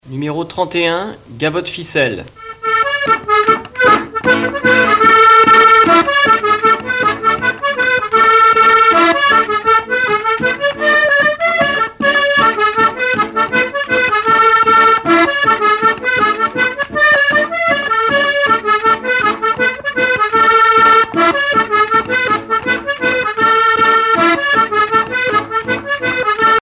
Localisation Plouray
danse : gavotte bretonne
Pièce musicale éditée